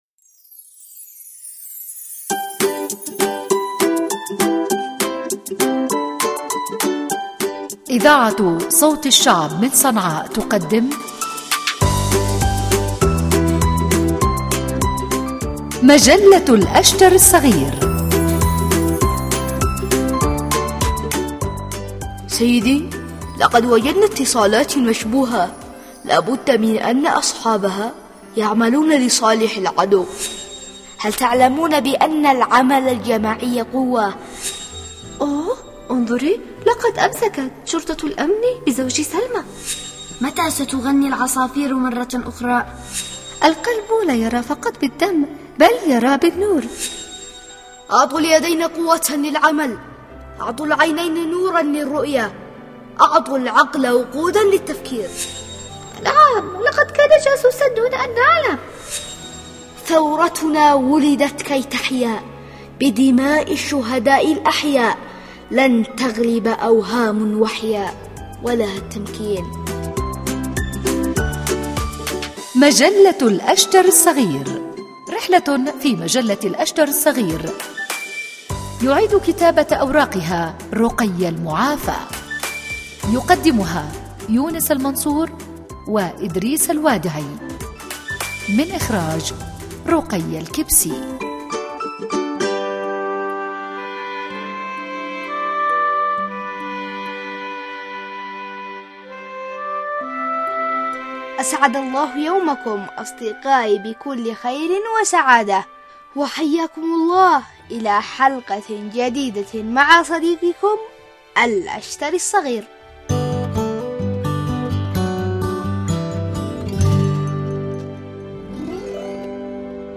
برامج الأطفال